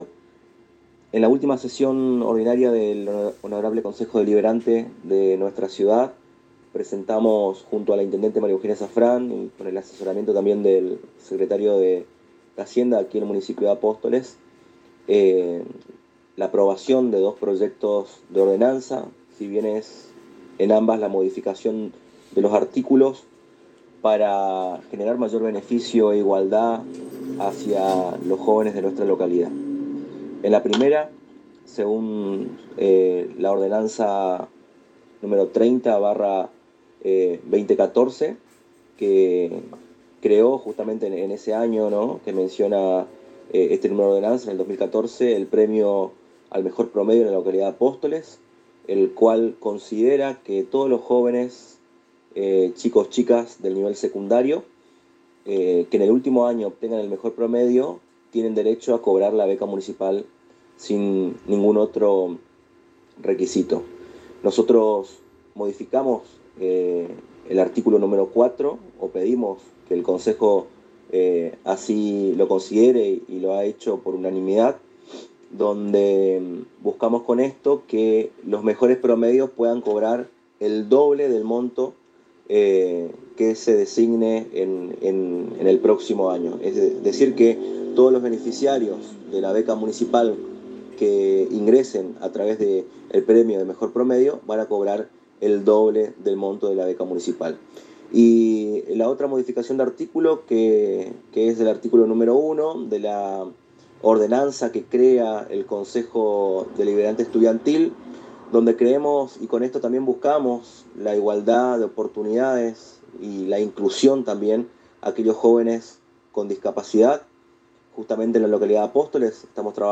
En diálogo exclusivo con la ANG el Director de la Juventud de Apóstoles Aldo Muñoz explicó cuales fueron las modificaciones a las Ordenanzas 30/2014 que se incorpora a partir de su aprobación el premio al mejor promedio en el otorgamiento de la Beca Municipal. La segunda modificación impulsada por el Ejecutivo fue a la Ordenanza 18/2008 donde a partir de esta modificación se crea el Concejo Deliberante Inclusivo.